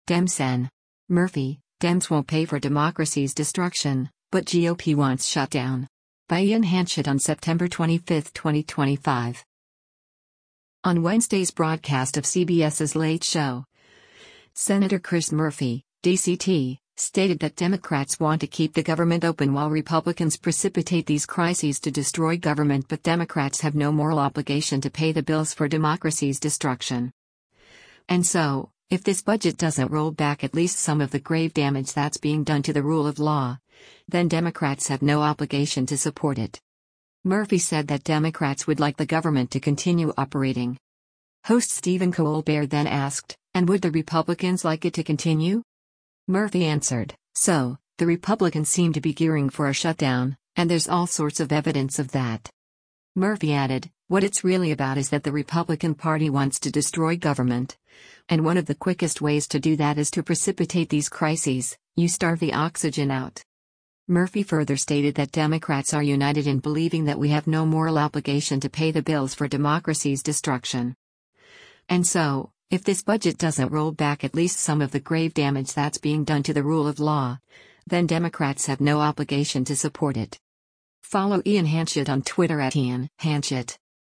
On Wednesday’s broadcast of CBS’s “Late Show,” Sen. Chris Murphy (D-CT) stated that Democrats want to keep the government open while Republicans “precipitate these crises” “to destroy government” but Democrats “have no moral obligation to pay the bills for democracy’s destruction.
Host Stephen Colbert then asked, “And would the Republicans like it to continue?”